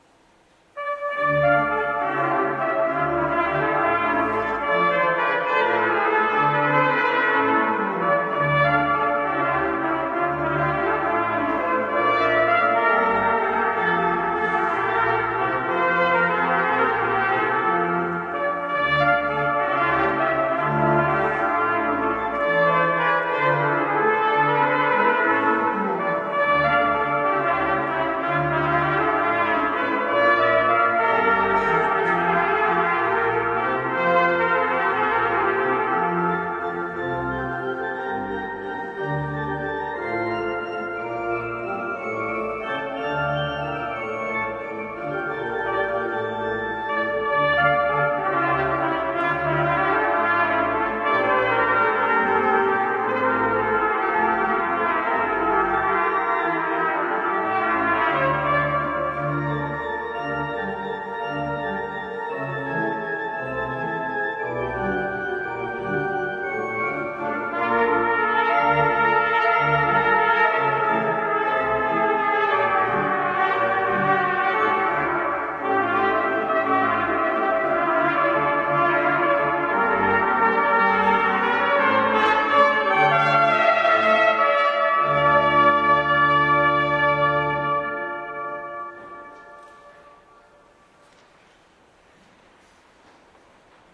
Koncert organowy „Krzyżu Chrystusa” 9 marca 2013
6. Antonio Vivaldi – Vivace z Koncertu d-moll na trąbkę i organy